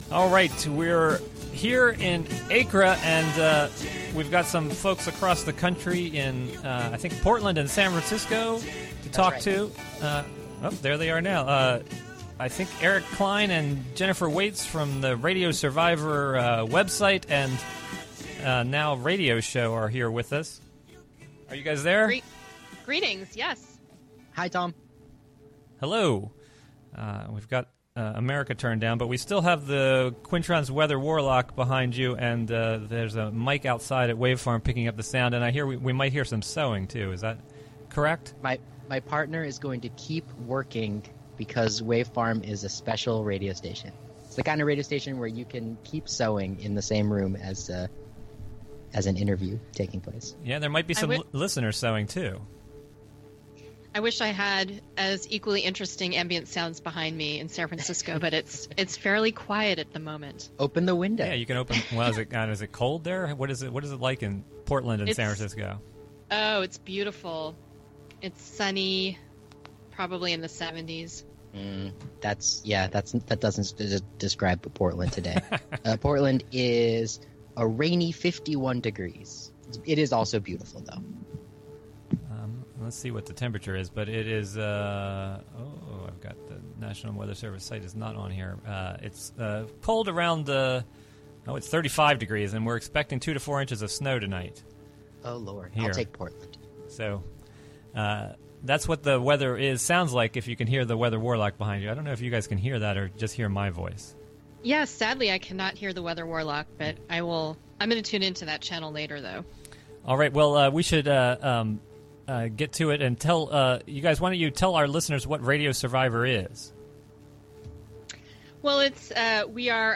Saturday Afternoon Show